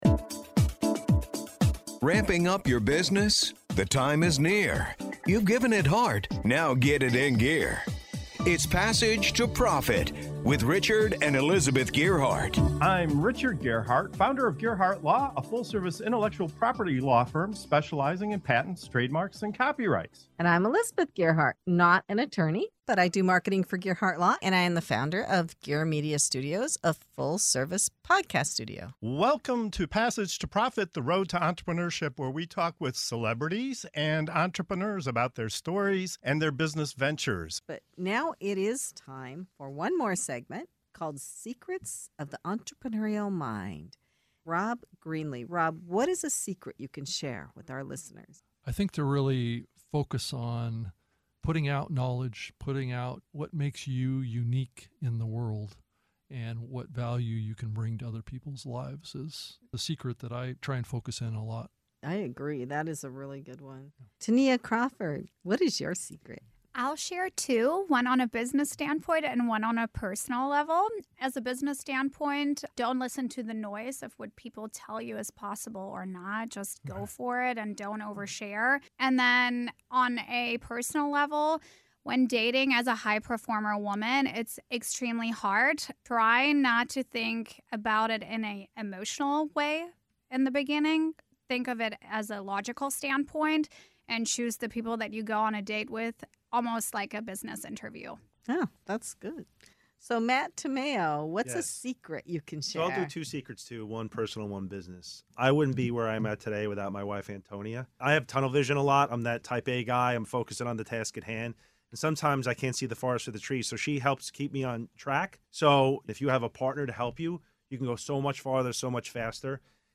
In this segment of “Secrets of the Entrepreneurial Mind” on Passage to Profit Show, our guests drop the filters and get real—sharing the personal and business truths that have shaped their journeys. From ignoring the noise and qualifying the right clients to navigating dating as a high-performing woman and learning from painful mistakes, these candid insights reveal what it really takes to build success and stay sane while doing it.